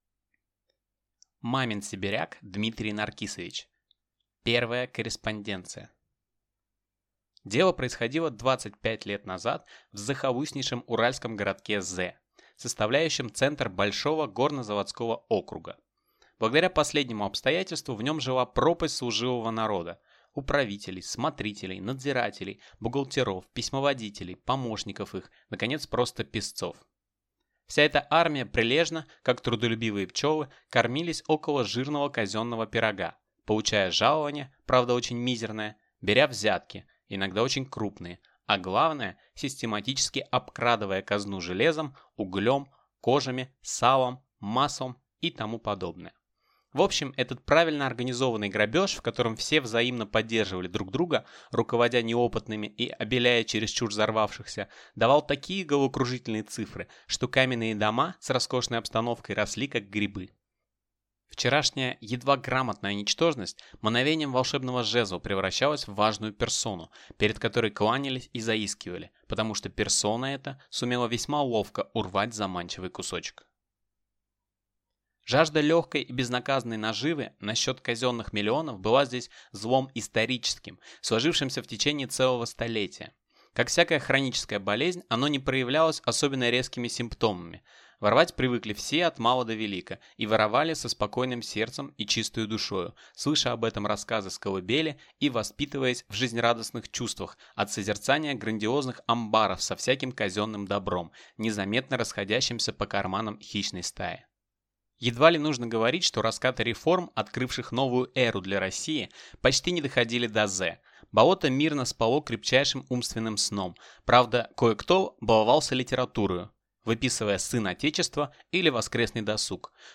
Аудиокнига Первая корреспонденция | Библиотека аудиокниг